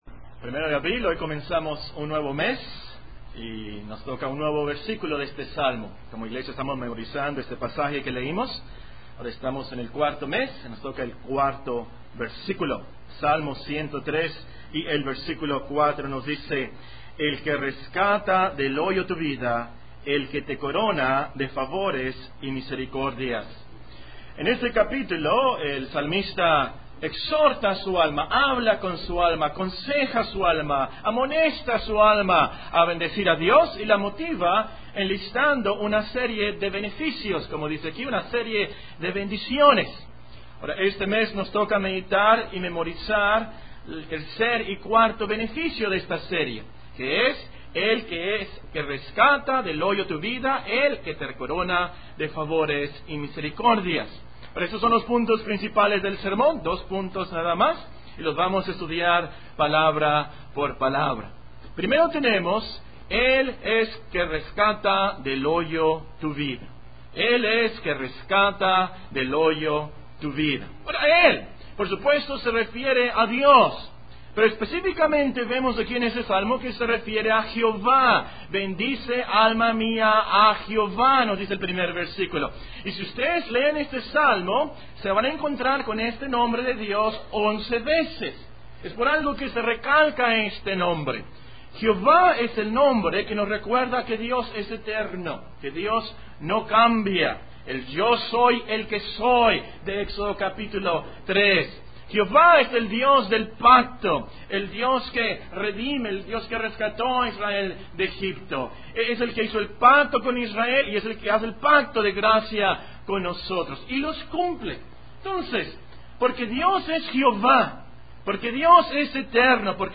Sermones Biblicos Reformados en Audio, porque la Fe viene por el Oir